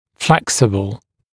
[‘fleksəbl][‘флэксэбл]гибкий, гнущийся